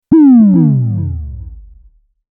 Deep Impact Bass Sound Effect
Description: Deep bass drop sound effect. Create impact with this deep bass drop sound effect, delivering a heavy sub bass hit and strong low-frequency punch.
Genres: Sound Logo
Deep-impact-bass-sound-effect.mp3